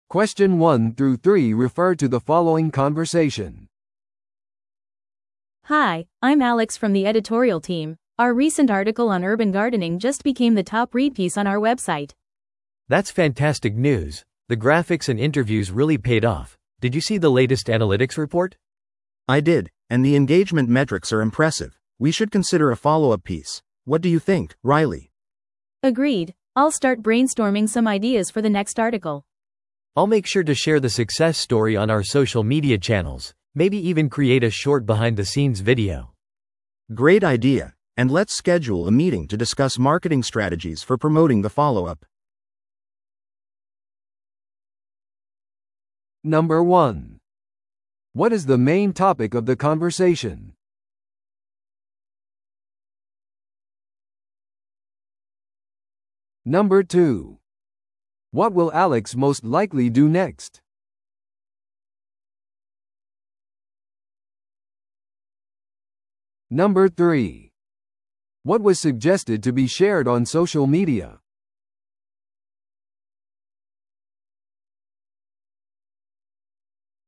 TOEICⓇ対策 Part 3｜都市ガーデニング記事の成功と次回案について – 音声付き No.269